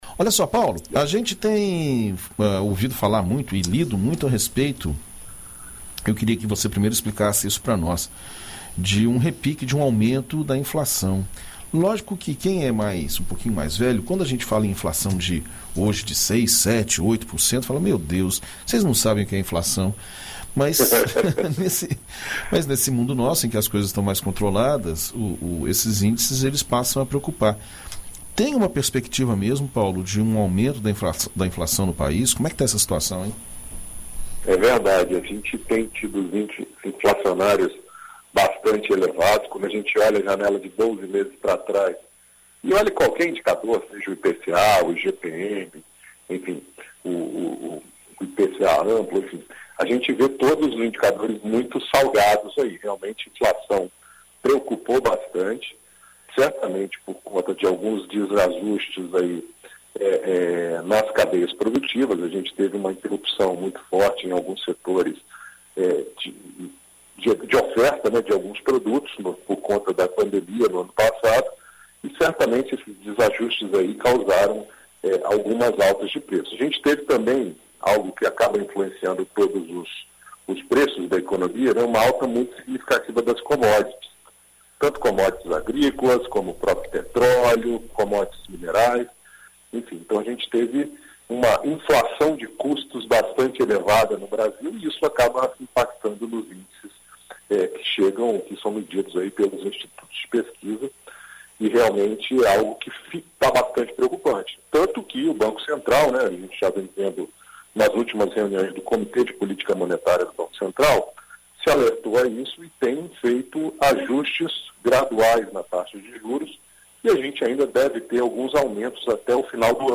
Na coluna Seu Dinheiro desta terça-feira (15), na BandNews FM Espírito Santo